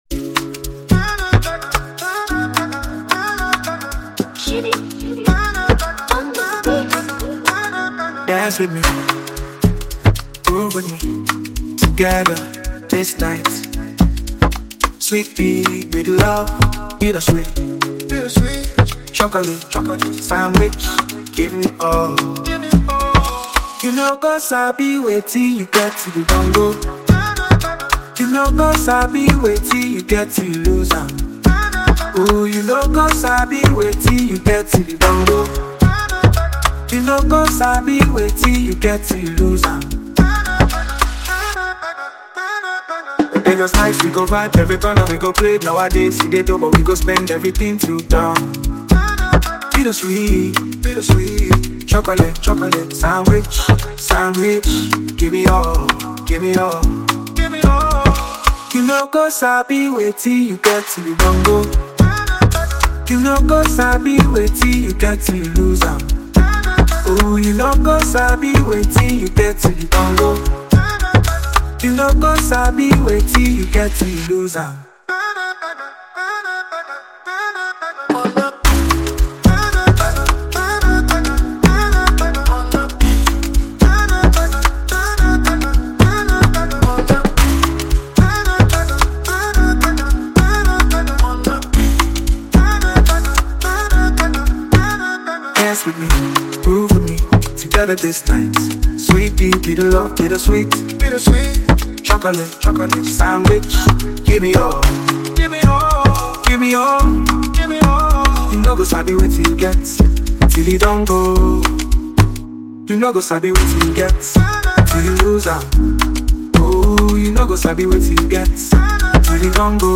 With its infectious melody and relatable lyrics